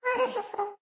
moan7.ogg